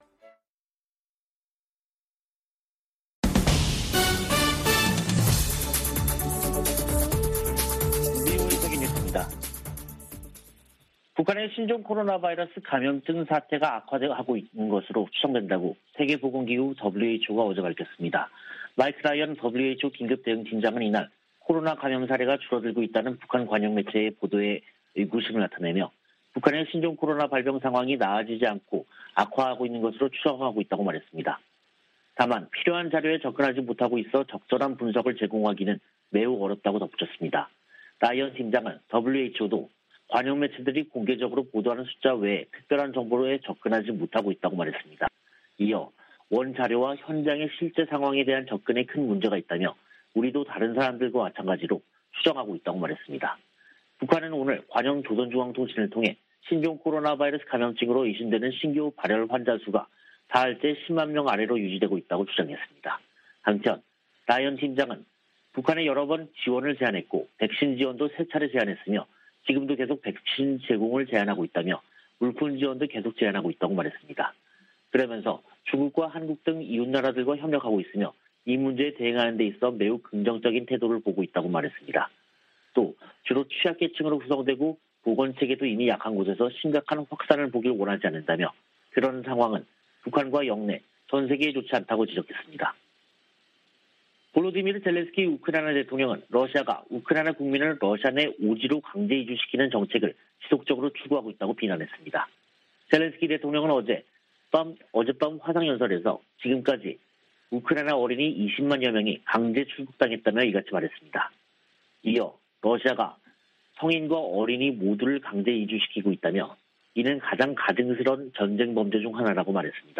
VOA 한국어 간판 뉴스 프로그램 '뉴스 투데이', 2022년 6월 2일 3부 방송입니다. 북한의 7차 핵실험 준비 동향이 잇따라 포착되고 있는 가운데 3일 미·한·일 북 핵 수석대표들이 서울에 모여 대책을 논의합니다. 토니 블링컨 미 국무장관은 중국이 국제 현안들을 해결하는데 중요한 역할을 해야 한다며 그 중 하나로 북한 핵 문제를 꼽았습니다. 주한미군사령관과 일본 자위대 수장이 긴급 회동했습니다.